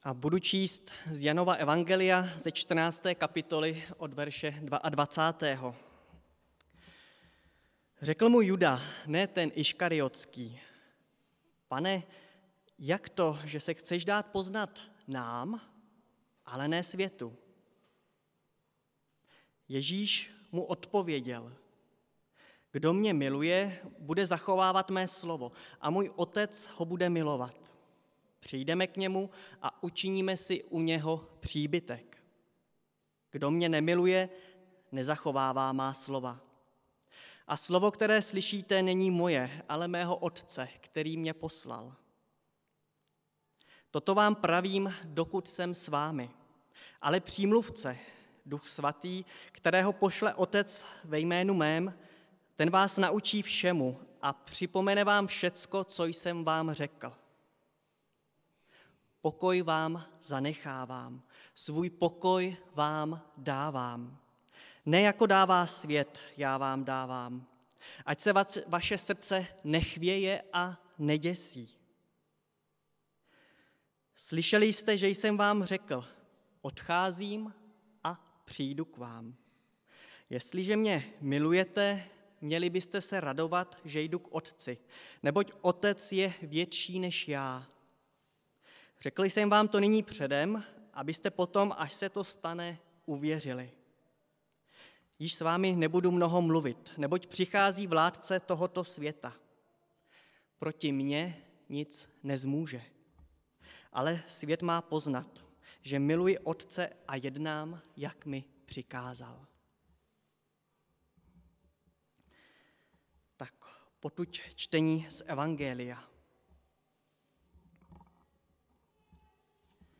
Nedělení kázání – 6.6.2022 Kázání na letnice